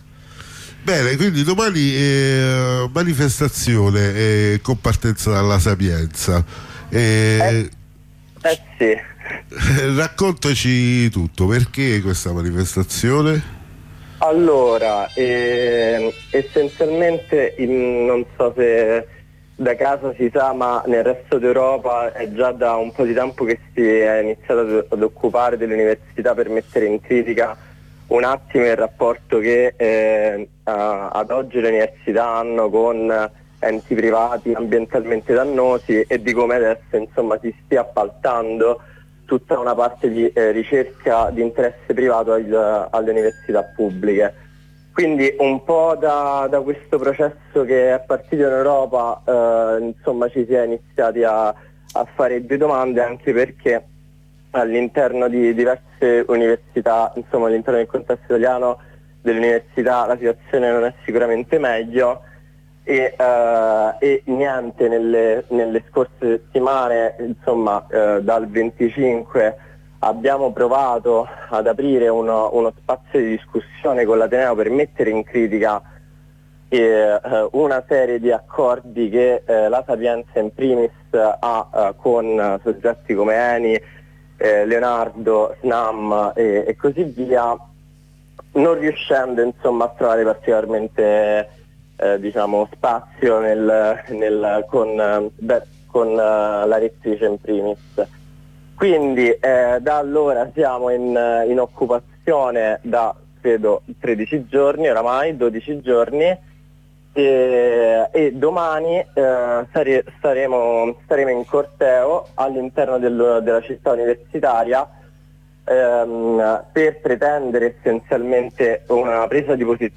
Ne parliamo con due studenti della Sapienza impegnate nella mobilitazione.